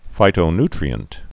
(fītō-ntrē-ənt, -ny-)